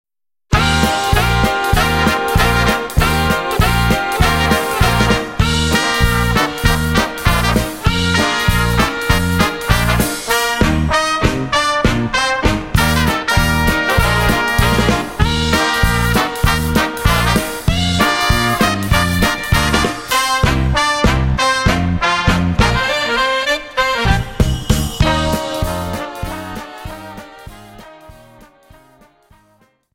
QUICK STEP:49(4+96)